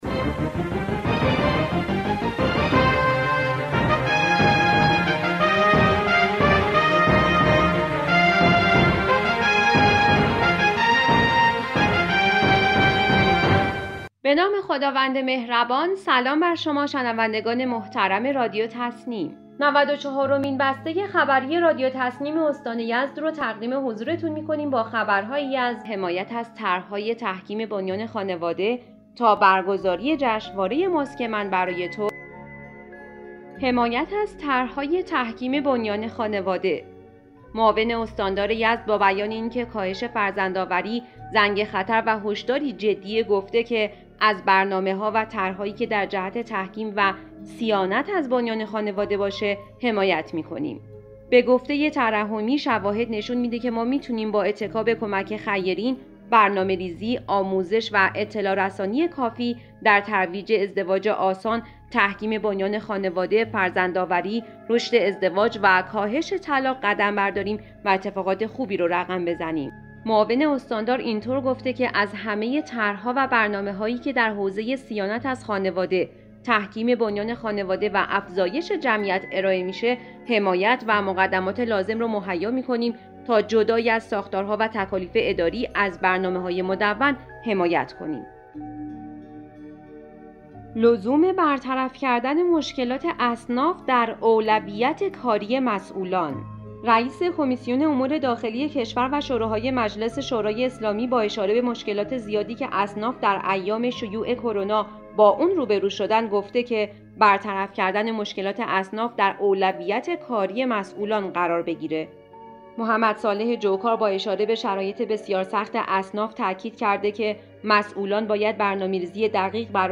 به گزارش خبرگزاری تسنیم از یزد, نود و چهارمین بسته خبری رادیو تسنیم استان یزد با خبرهایی از توصیه معاون استاندار در مورد تحکیم بنیان خانواده, تاکید نماینده مردم یزد در مجلس شورای اسلامی در مورد حل مشکلات اصناف, انتقال سنگ‌فروشی‌های یزد به خارج شهر, افزایش قیمت شیر و برگزاری جشنواره ماسک من برای تو منتشر شد.